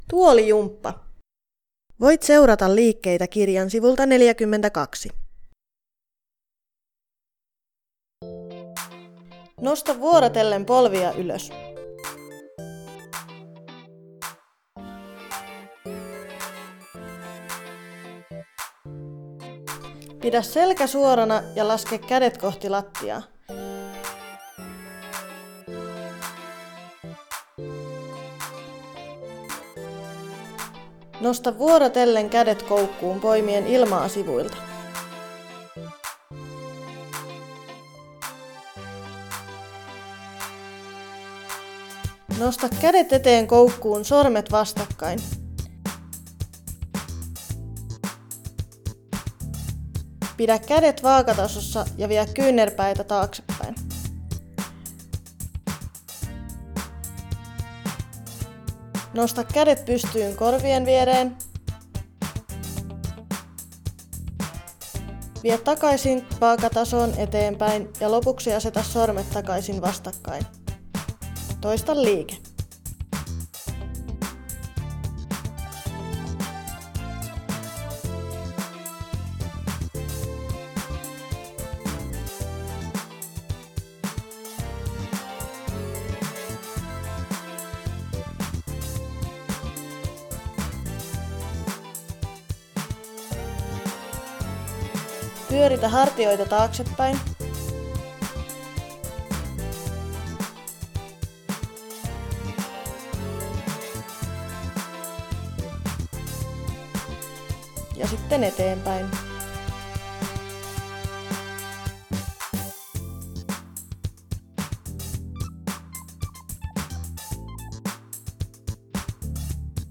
TOM-hankkeessa on valmistettu Muistityökirja "Muistin tähäre" ja siihen liittyvä äänikirja.